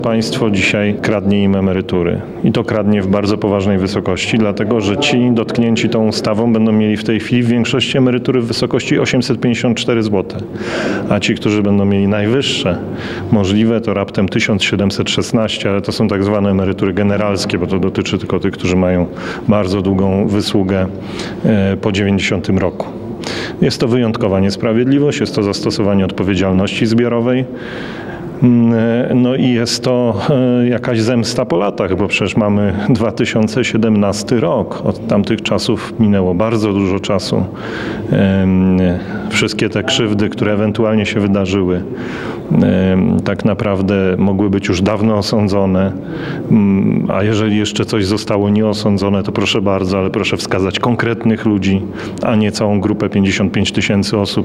– Jest to niesprawiedliwa zemsta po latach i stosowanie odpowiedzialności zbiorowej – mówił w rozmowie z Radiem 5 Rozenek.